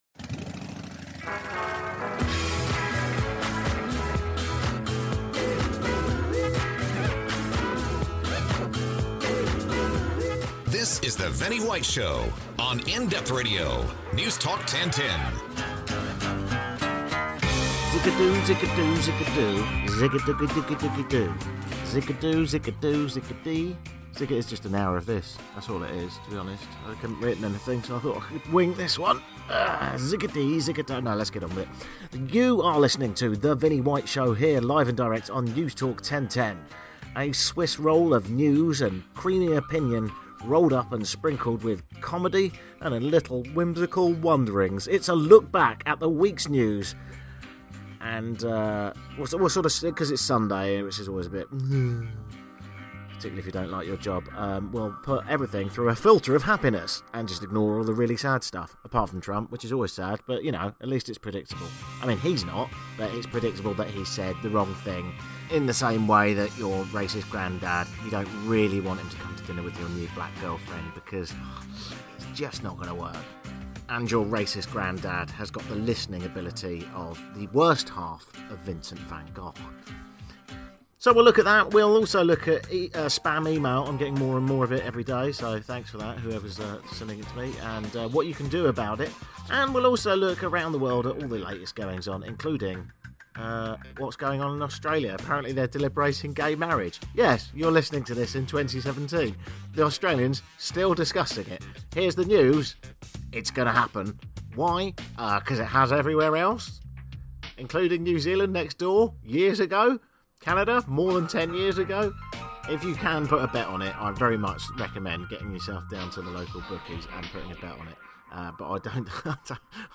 Also; we get an update (good news) on the wild fires of Western Canada by phone.